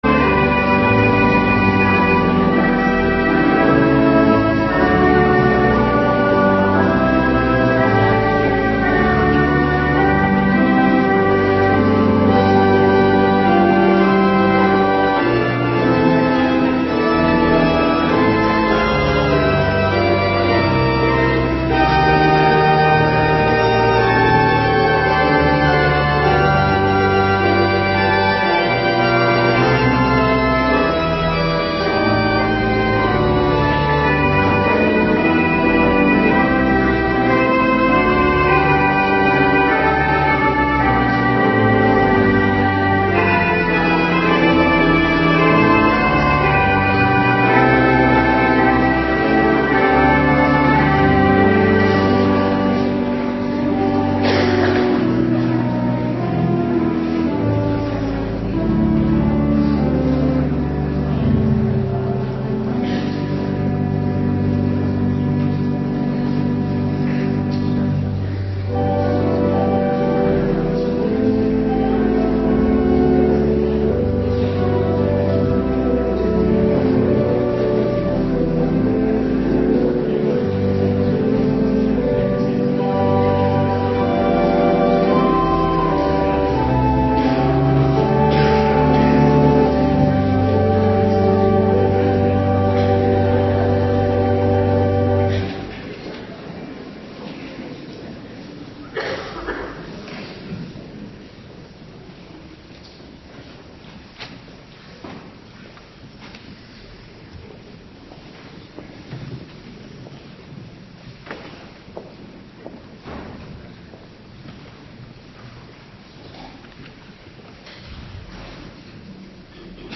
Morgendienst 18 januari 2026